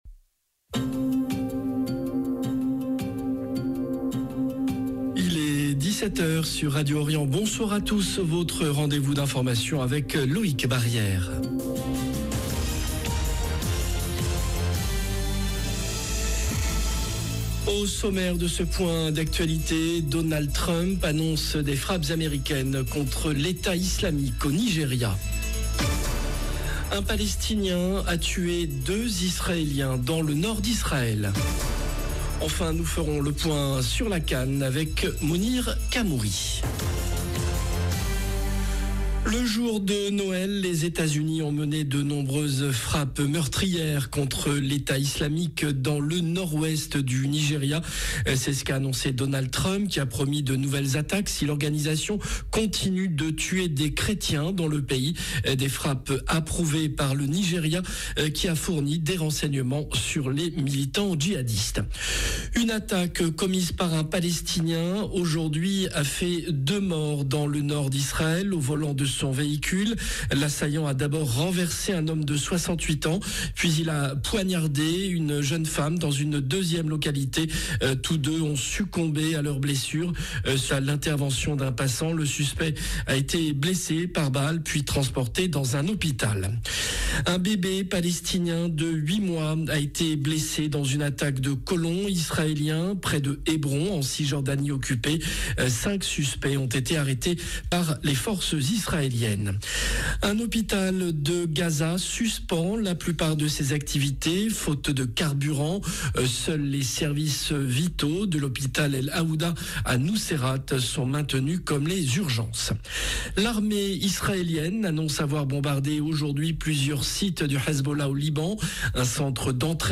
JOURNAL DE 17H Au sommaire : Donald Trump annonce des frappes américaines contre Daech au Nigeria. Un Palestinien a tué 2 Israéliens dans le nord d’Israël.